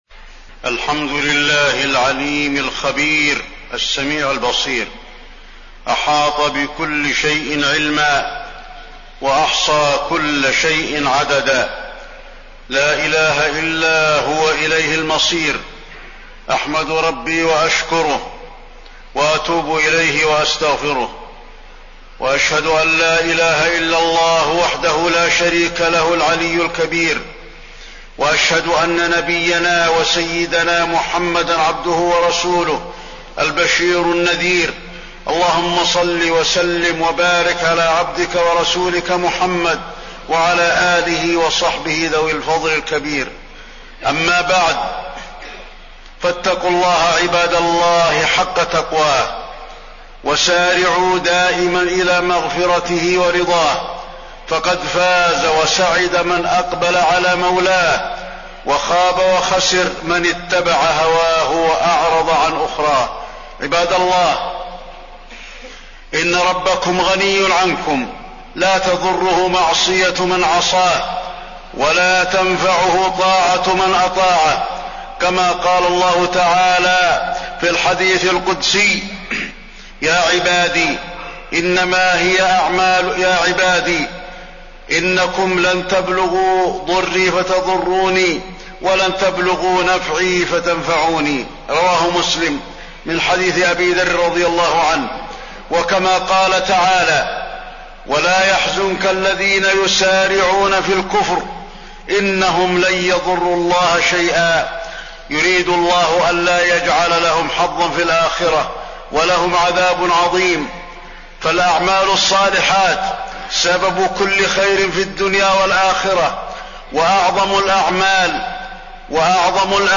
تاريخ النشر ٢٧ ربيع الثاني ١٤٣٢ هـ المكان: المسجد النبوي الشيخ: فضيلة الشيخ د. علي بن عبدالرحمن الحذيفي فضيلة الشيخ د. علي بن عبدالرحمن الحذيفي الحذر من سوء الخاتمة